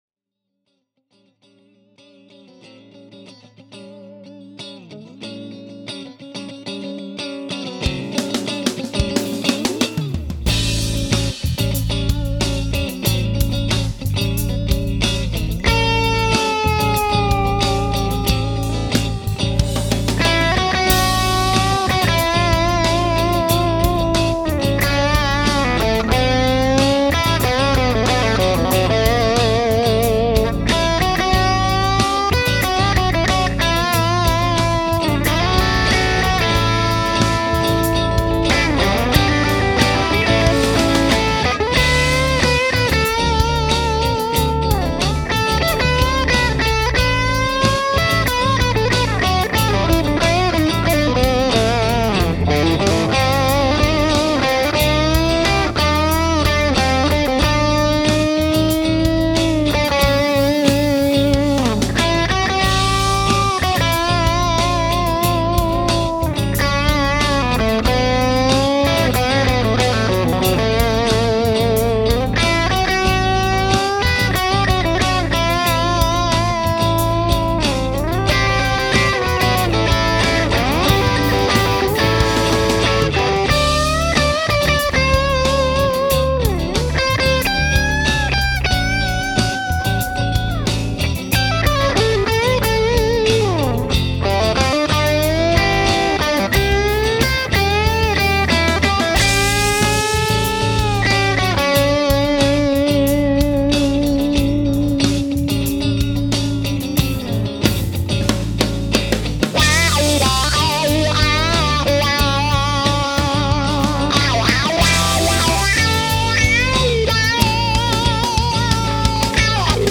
Here’s the original, recorded with the PLX18 BB with the stock Red Coat.
But where the Red Fang has much more presence, and an in-your-face presentation, the Medusa’s tone is so much more three-dimensional and more refined.
Note that the amp and mix settings stayed completely the same between the two recordings, and both guitars were played through the Trem channel which was completely dimed.
By the way, both clips were recorded at conversation levels using the fantastic Aracom PRX150-Pro attenuator, by far the best attenuator on the planet, from my perspective.